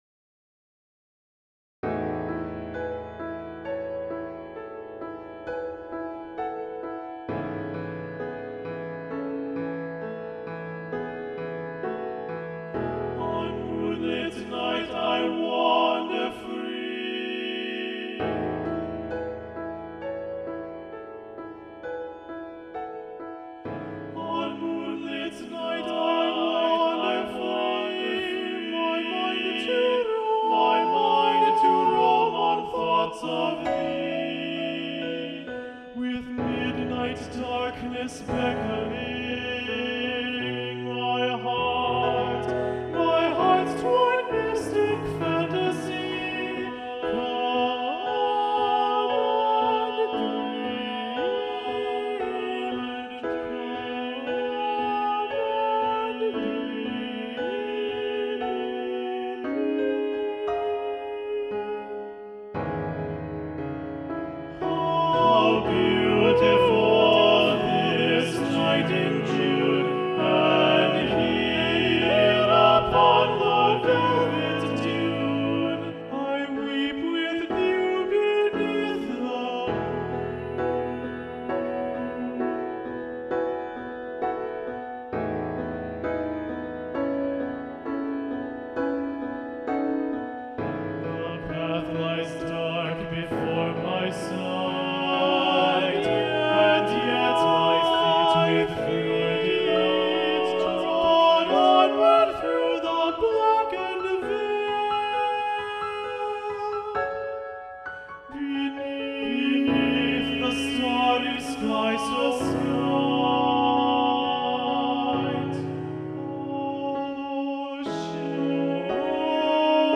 Ballade-to-the-Moon-Balanced-Voices-Daniel-Elder.mp3